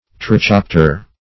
trichopter - definition of trichopter - synonyms, pronunciation, spelling from Free Dictionary Search Result for " trichopter" : The Collaborative International Dictionary of English v.0.48: Trichopter \Tri*chop"ter\, n. (Zool.)